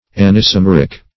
Search Result for " anisomeric" : The Collaborative International Dictionary of English v.0.48: Anisomeric \An`i*so*mer"ic\, a. [Gr.
anisomeric.mp3